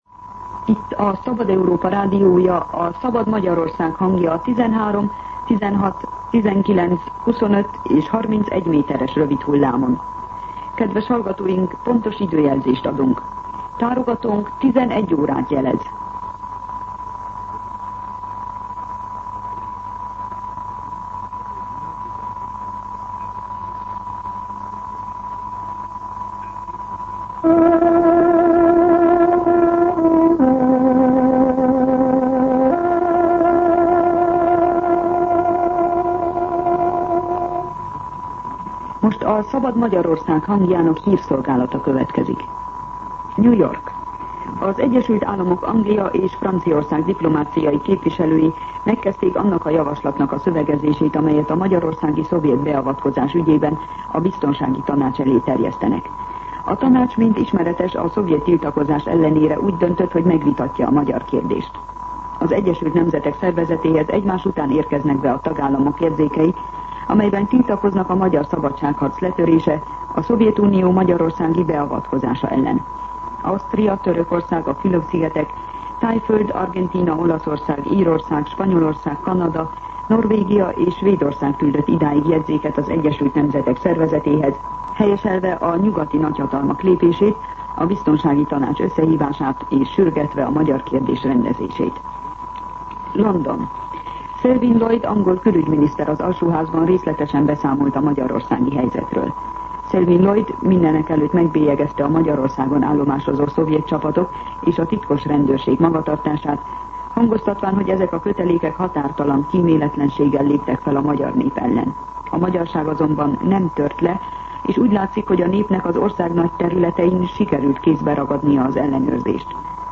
Hírszolgálat